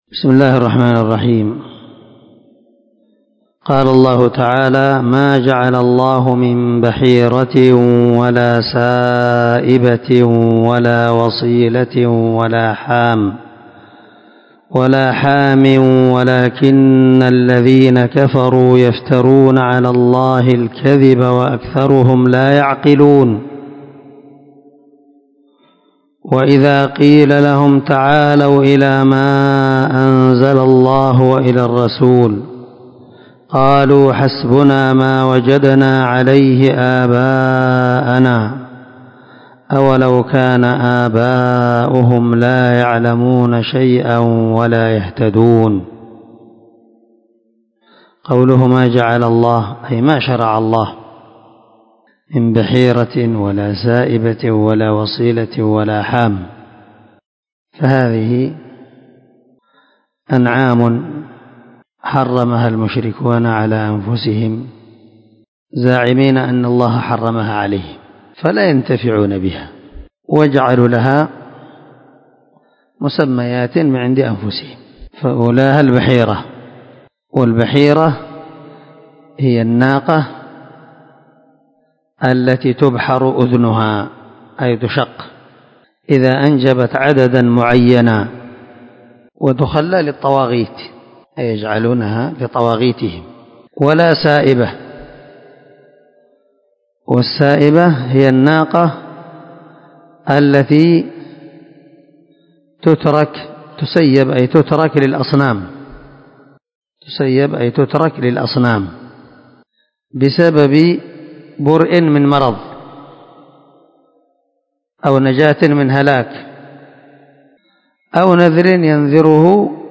388الدرس 54 تفسير آية ( 103 - 104 ) من سورة المائدة من تفسير القران الكريم مع قراءة لتفسير السعدي